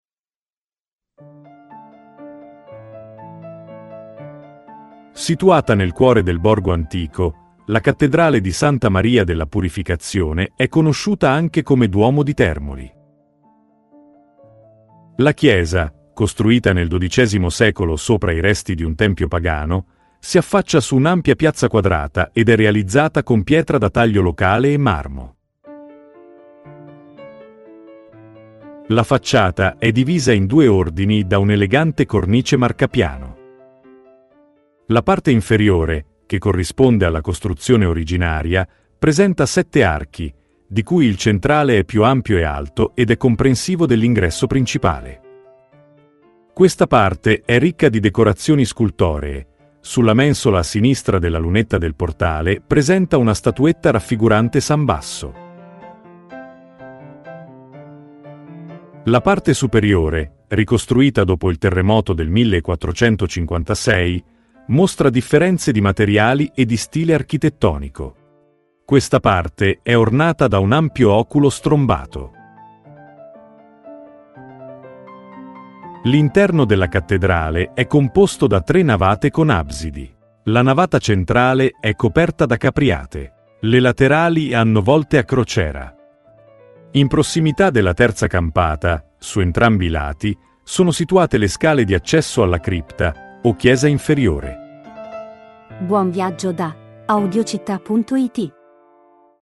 Audioguida Termoli – La Cattedrale